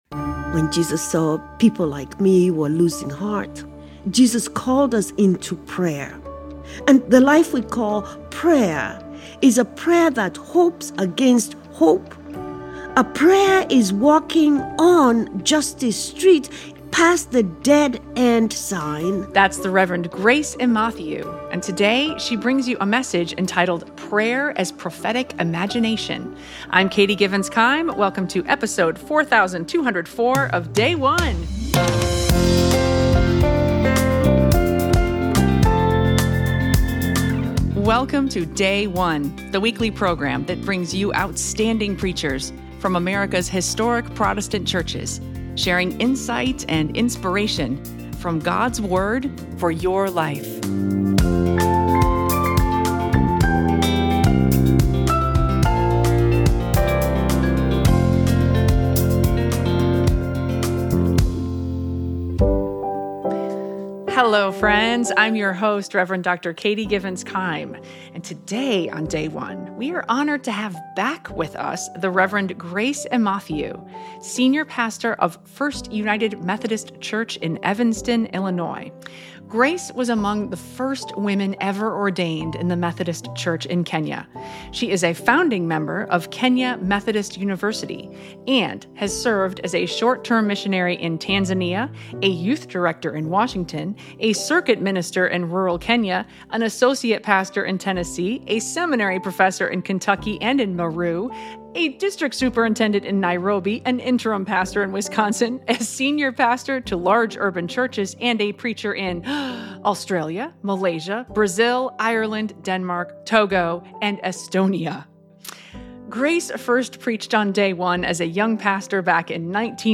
United Methodist Church 19th Sunday after Pentecost - Proper 24 (29) - Year C Luke 18:1–8